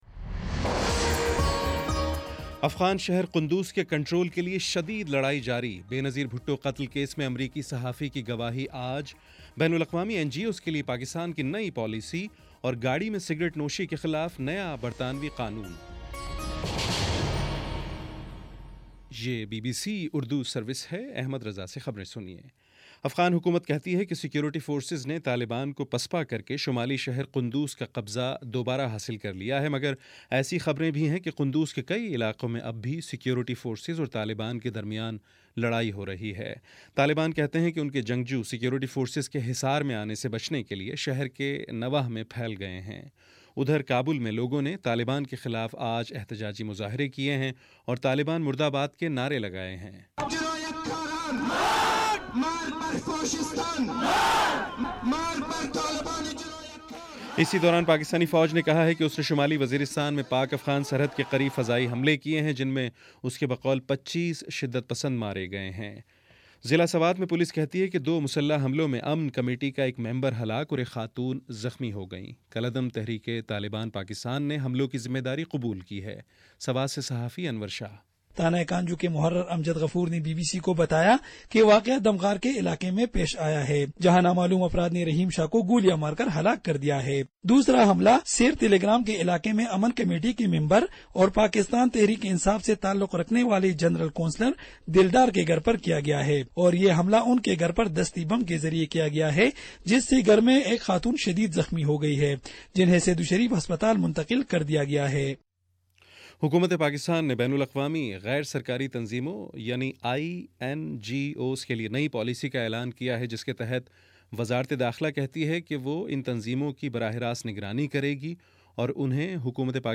اکتوبر 1 : شام سات بجے کا نیوز بُلیٹن